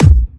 Kick f.wav